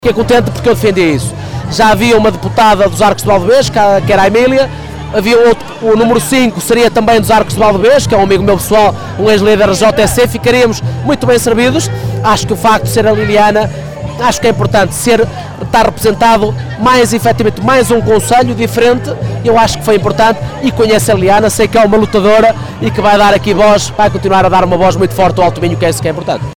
Aos microfones da Rádio Vale do Minho, o edil monçanense mostrou-se radiante.